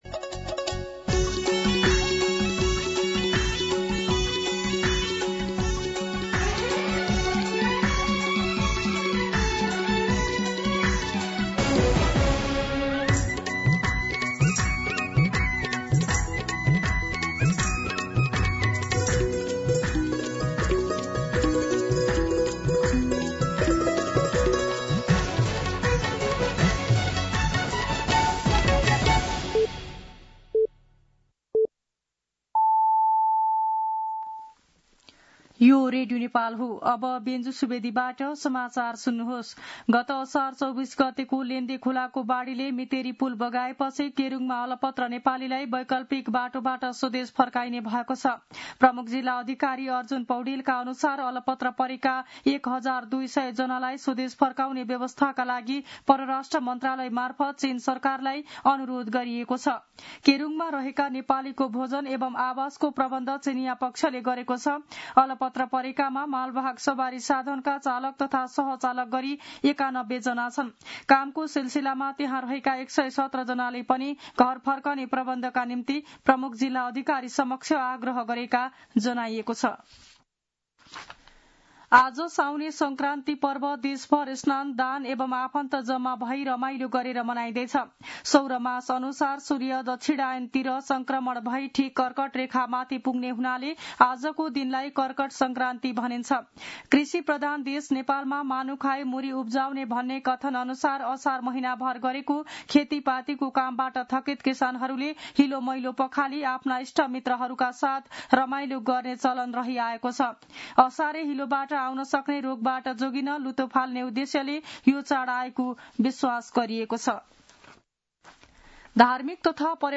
दिउँसो १ बजेको नेपाली समाचार : १ साउन , २०८२